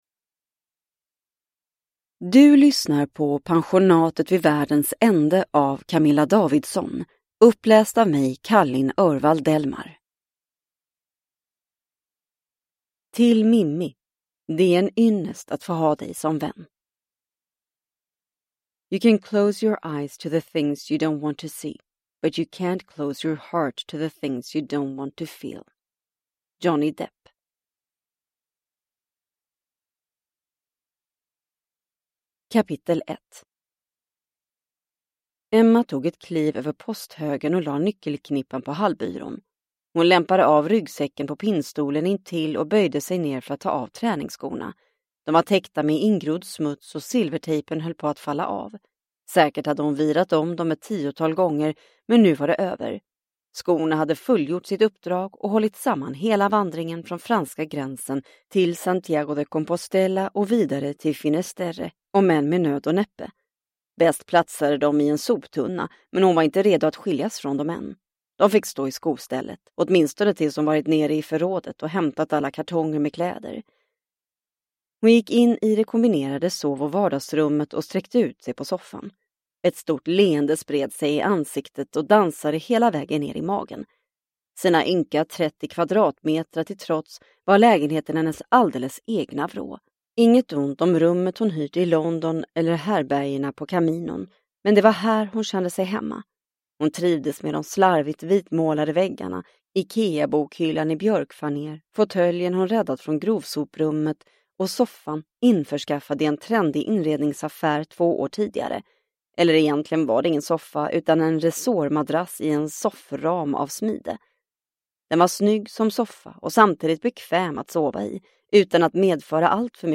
Pensionatet vid världens ände – Ljudbok – Laddas ner